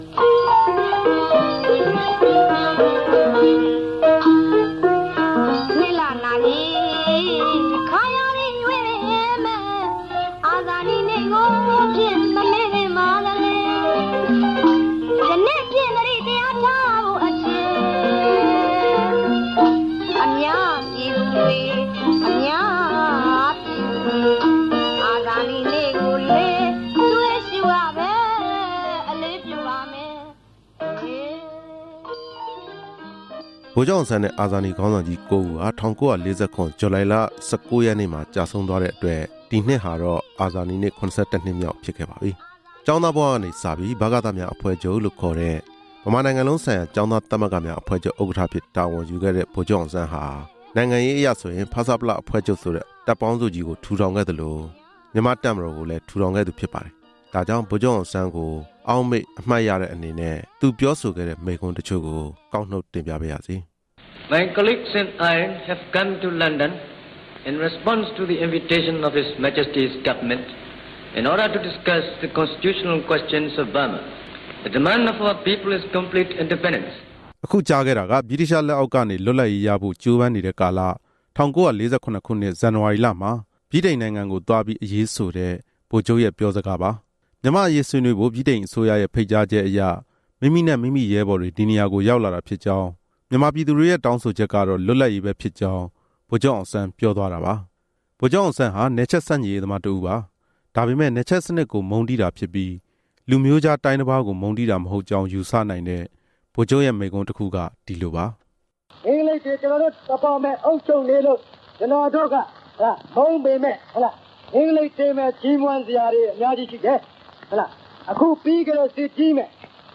ဗိုလ်ချုပ်အောင်ဆန်းမိန့်ခွန်းကောက်နှုတ်ချက်